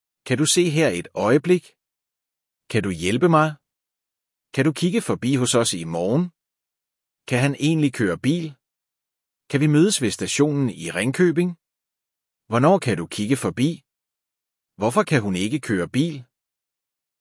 • Kannst du mal gucken? - Kan du se her et øjeblik? - Känn duu ßee här ett oijeblikk?
• Kannst du mir helfen? - Kan du hjælpe mig? - Känn duu jällpe maj?